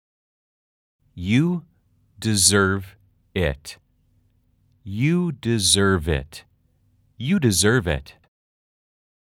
아주 천천히-천천히-빠르게 3번 반복됩니다.
/ 유 디저어빗 /
deserve it /디저어브 잇/이 아니라 붙여서 /디저어빗/으로 발음하세요.